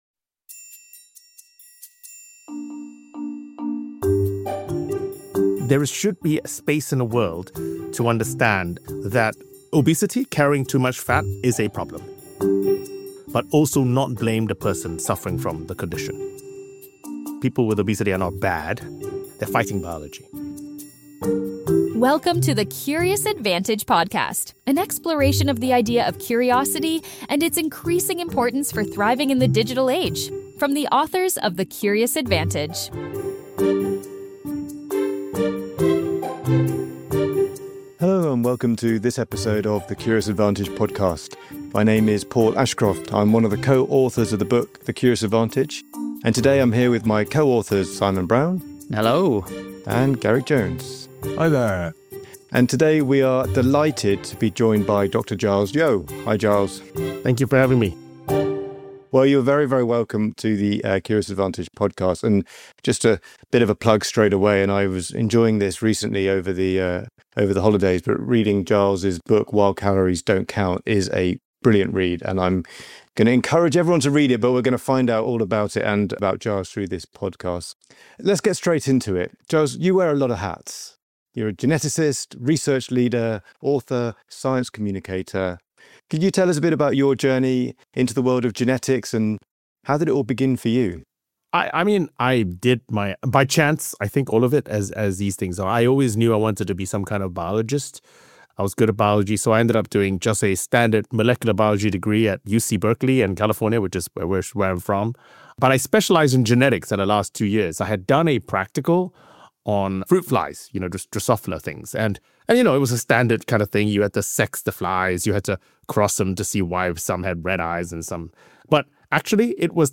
#81 Weight Loss, Ozempic, and the End of Calorie Counting? A Conversation with Dr. Giles Yeo (Geneticist, University of Cambridge Professor, Author)